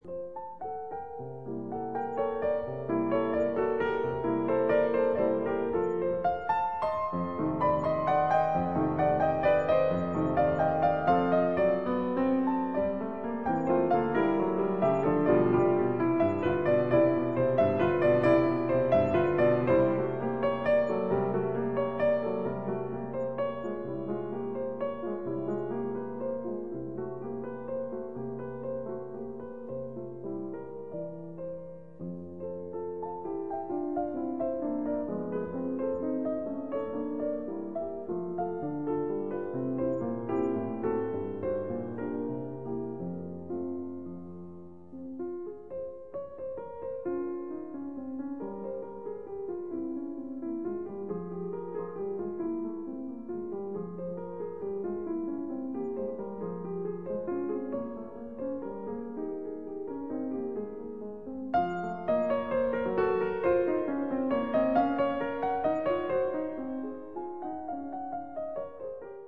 Tempo di minuetto con moto